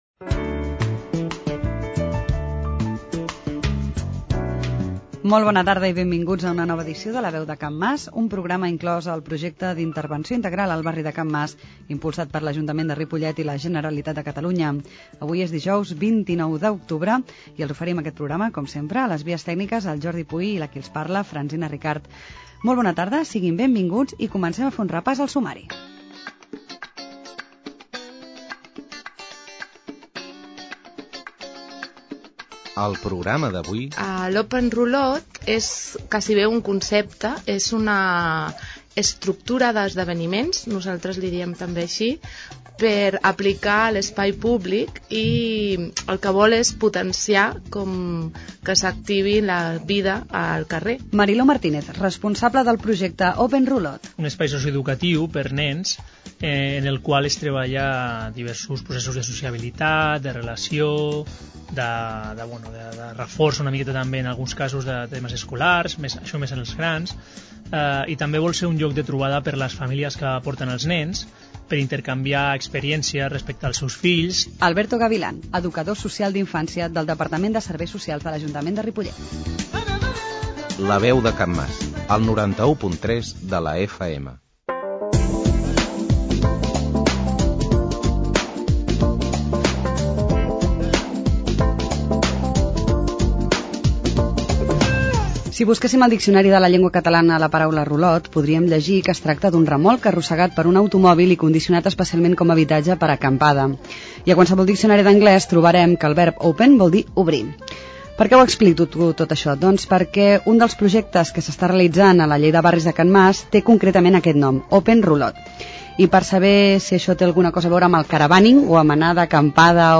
La Veu de Can Mas �s un programa de r�dio incl�s en el Projecte d'Intervenci� Integral al barri de Can Mas, que s'emet el darrer dijous de mes, de 19 a 19.30 hores i en redifusi� diumenge a les 11 del mat�.